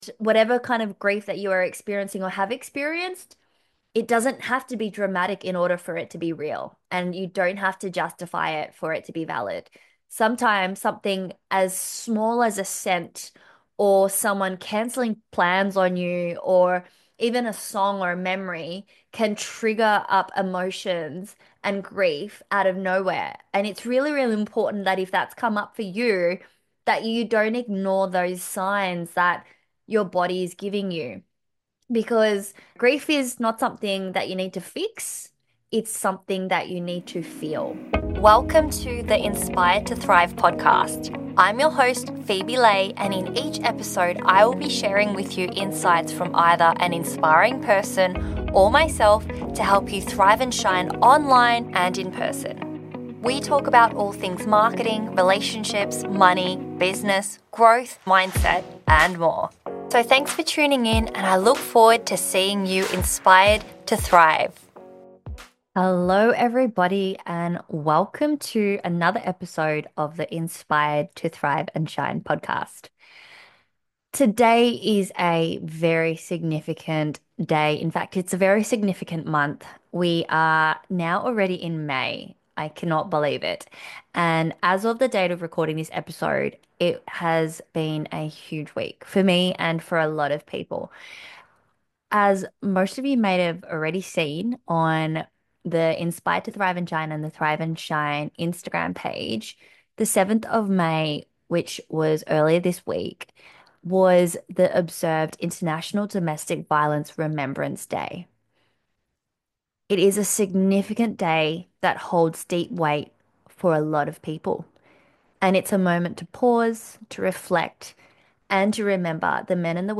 Unlock your potential with insights from global experts who’ve mastered the art of thriving. Through raw, powerful conversations with authors, speakers and experts who’ve "walked the talk," each episode fuels your journey to shine brighter in business and life.